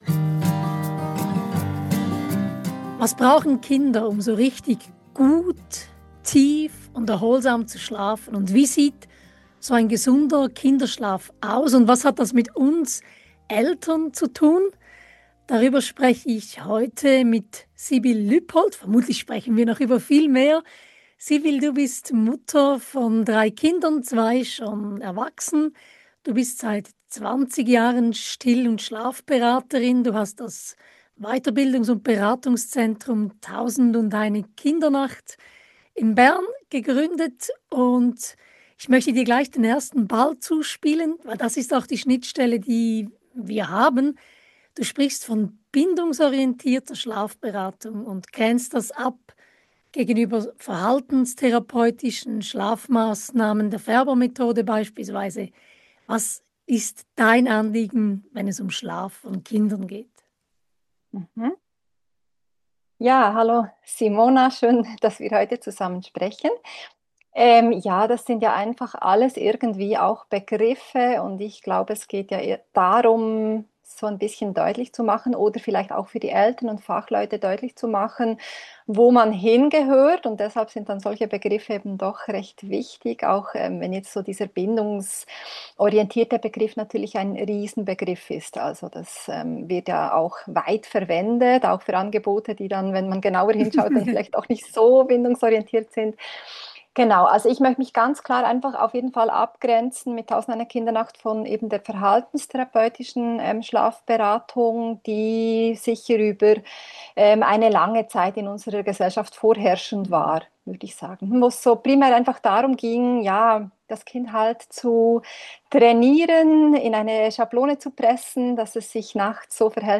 Das Gespräch ist richtig lang und auch persönlich geworden – und auch ziemlich umfassend.